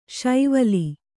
♪ Śaivali